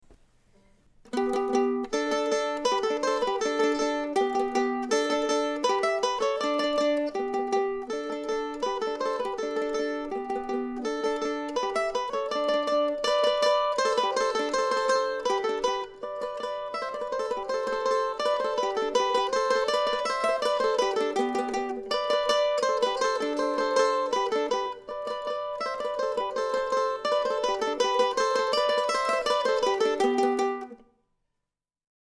Today also celebrates the completion of my project to record all 18 of the duettinos published by William Bates around 1770.